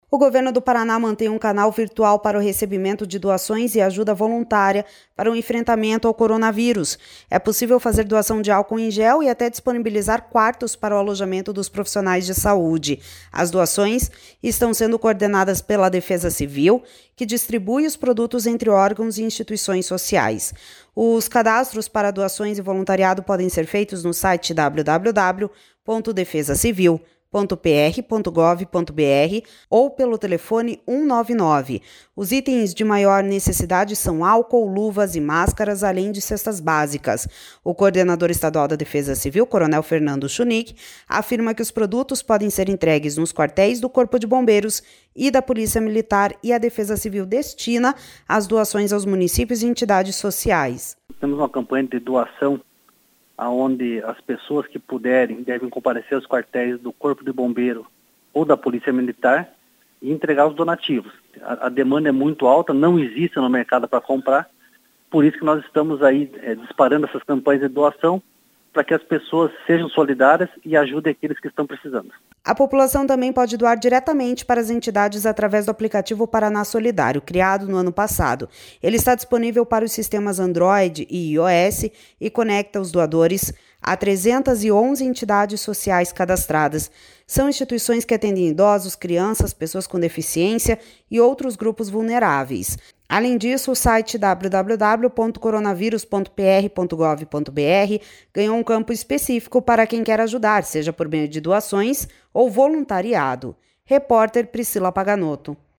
O coordenador estadual da Defesa Civil, coronel Fernando Schunig, afirma que os produtos podem ser entregues nos quartéis do Corpo de Bombeiros e da Polícia Militar e a Defesa Civil destina as doações aos municípios e entidades sociais.// SONORA FERNANDO SCHUNIG//A população também pode doar diretamente para as entidades através do aplicativo Paraná Solidário, criado no ano passado.